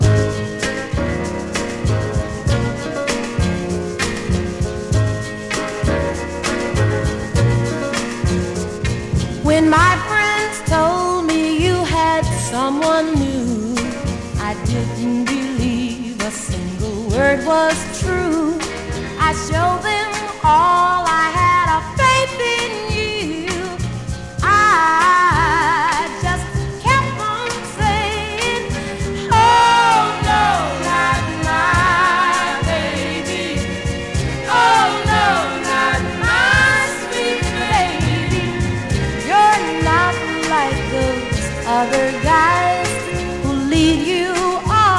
モッドなテイスト大盛りでこれまたある種の青春の香りが吹き上げます。
Soul, Funk　UK　12inchレコード　33rpm　Mono/Stereo